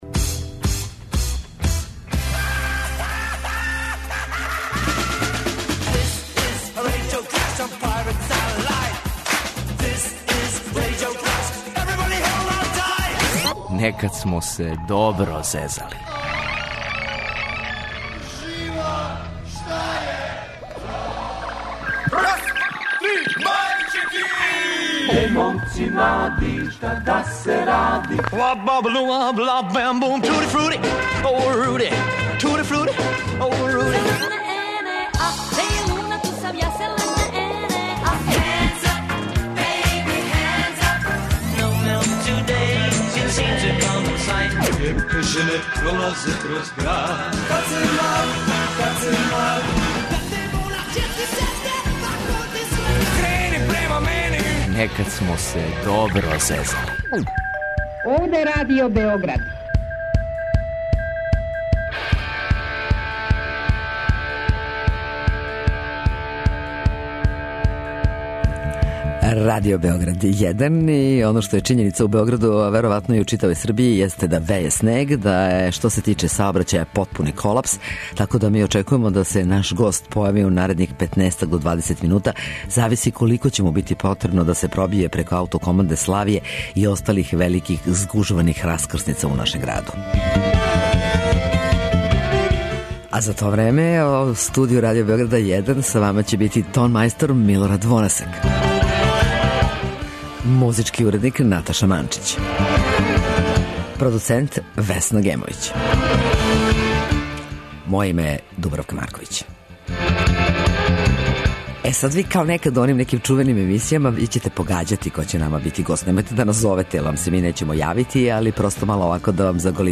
Гост емисије је Ненад Јанковић, познатији као Неле Карајлић - рок музичар, композитор, глумац, писац, некадашњи фронтмен сарајевске групе 'Забрањено пушење'.